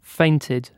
Uttal
Uttal UK Ordet hittades på dessa språk: engelska Ingen översättning hittades i den valda målspråket.